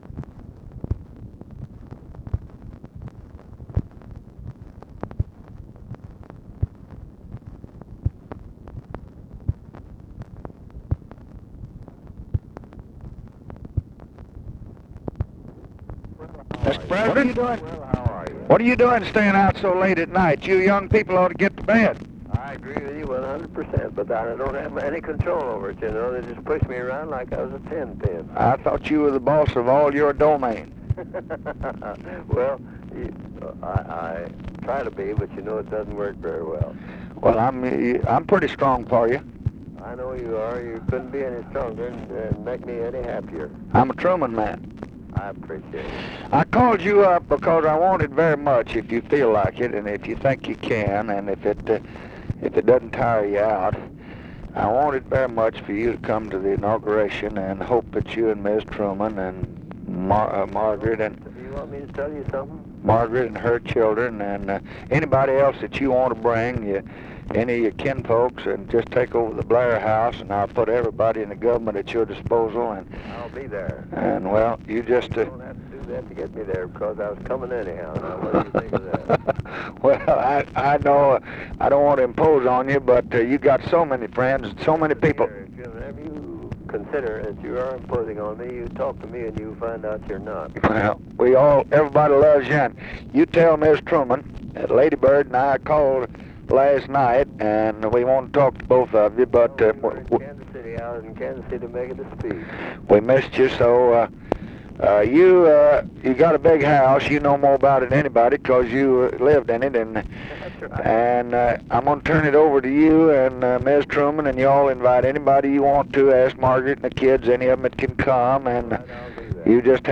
Conversation with HARRY TRUMAN, December 14, 1964
Secret White House Tapes